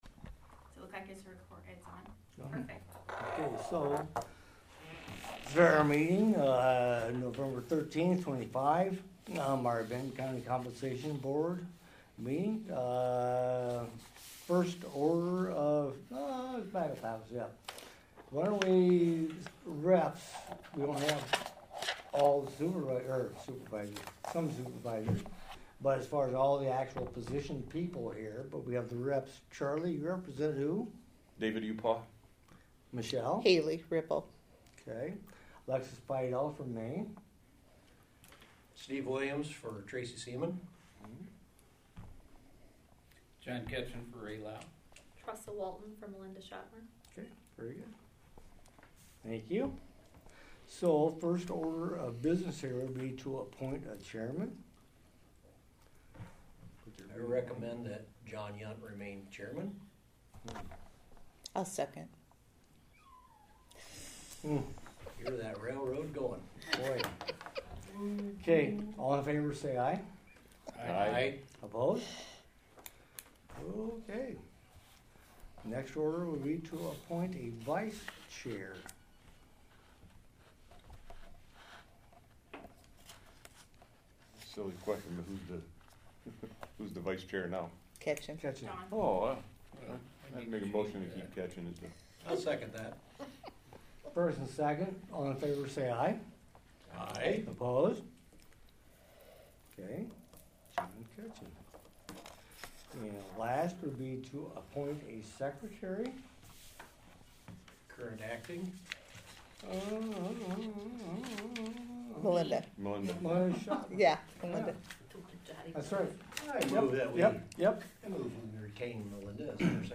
VINTON – The Benton County Compensation Board voted to “hold the budget flat” and recommended no salary increases for county elected officials at their meeting held Thursday, Nov. 13, at the service center in Vinton.